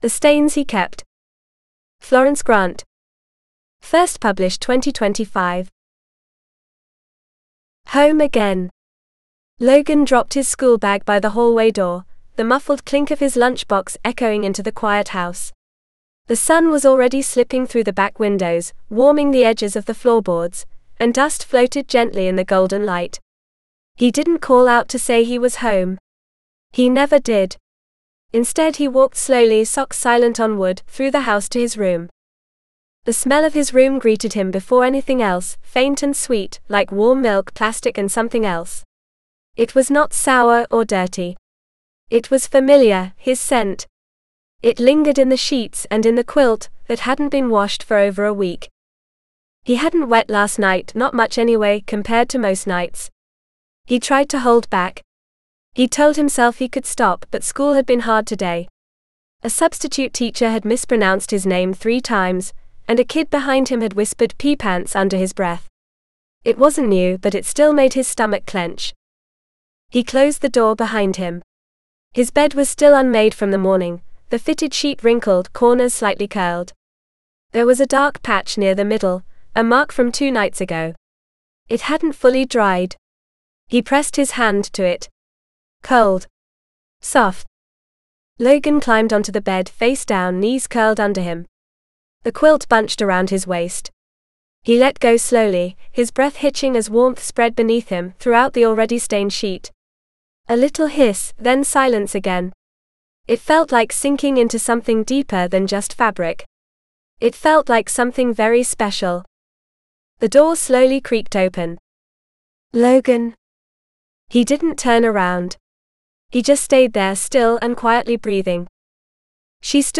The Stains He Kept  (AUDIOBOOK – female): $US3.99